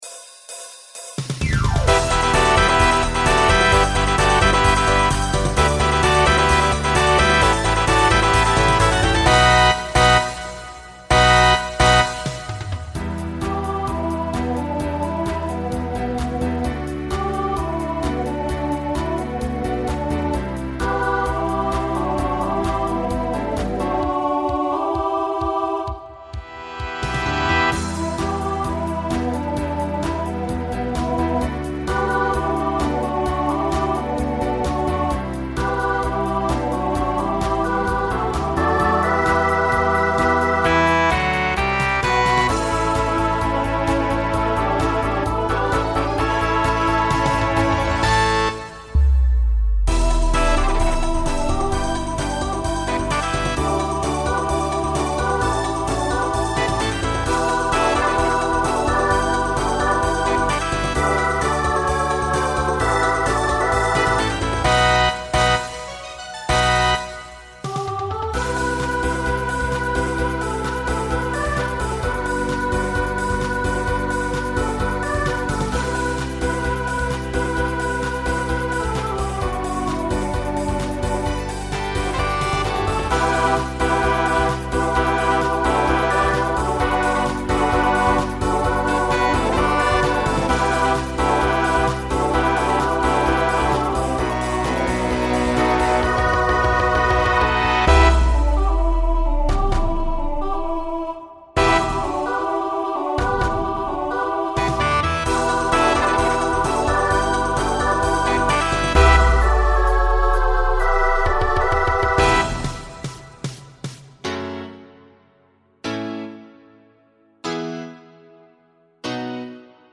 SSA/TTB
Voicing Mixed
Pop/Dance , Rock